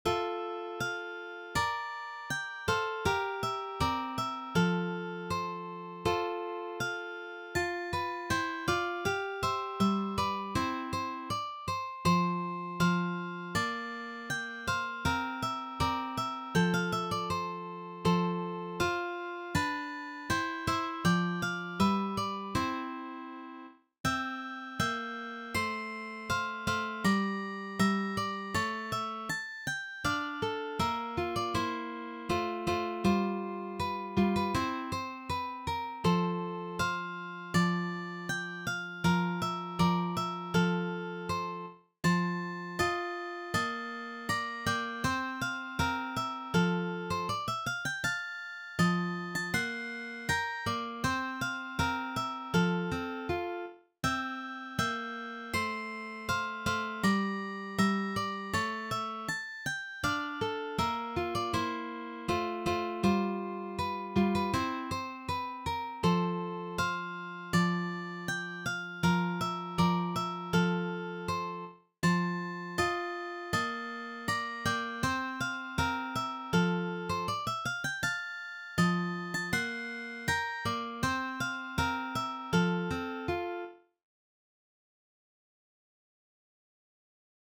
arranged for three guitars
This Classical selection is arranged for guitar trio.